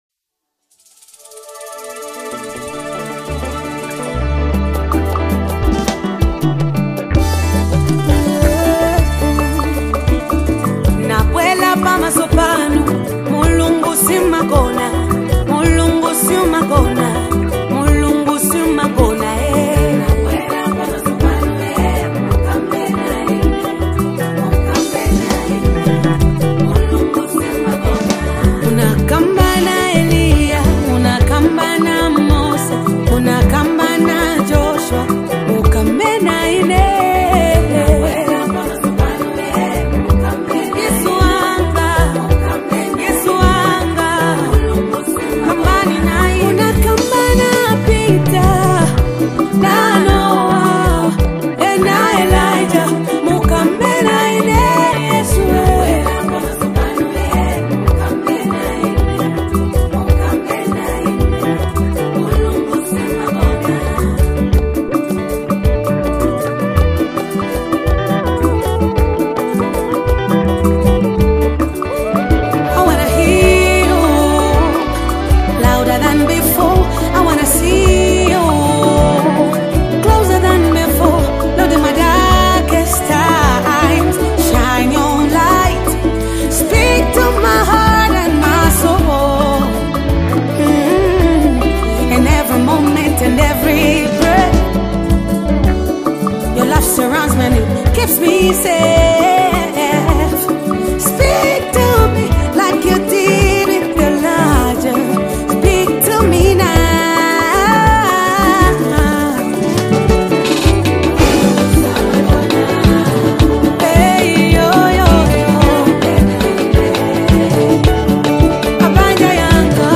RumbaZambian Worship Songs
Latest Zambian Worship Song 2025